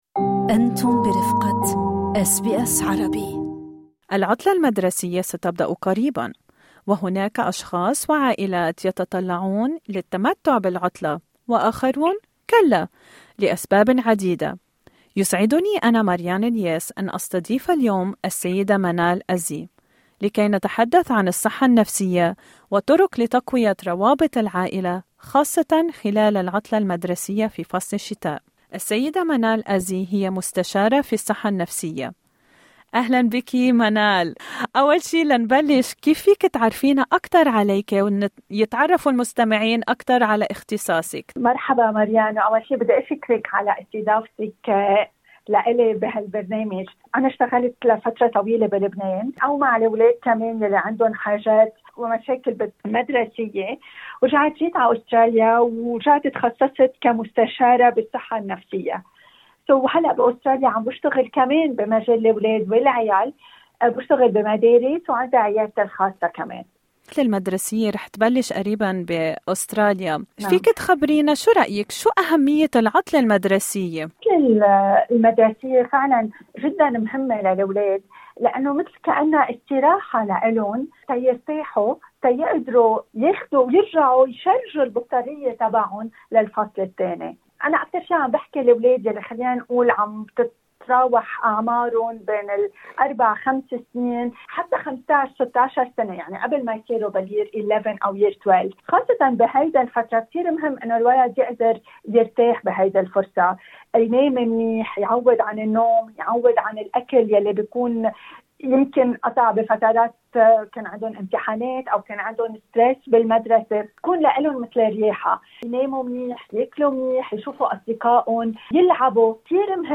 Registered Counsellor